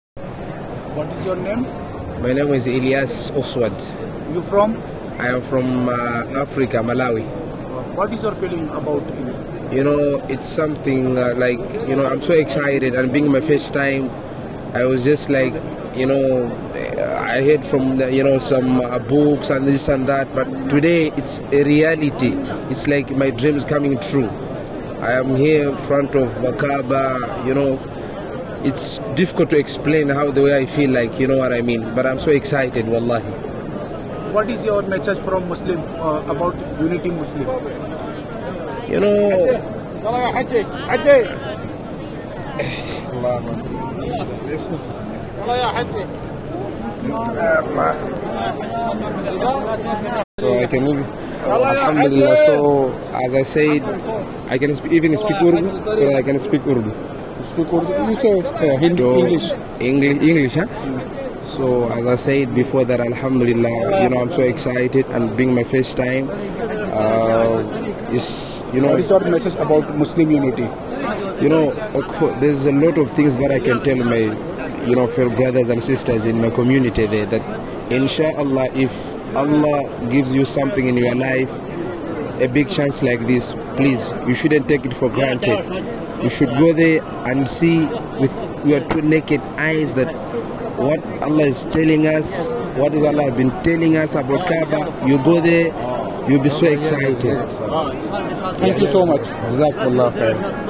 In an interview conducted in Mecca, Hajj pilgrims who have attended from every corner of the world expressed their feelings, saying they are delighted to be before Kaaba.
Similarly, a middle-aged woman who has gone on a pilgrimage to Mecca from Indonesia expressed her happiness and satisfaction with being here.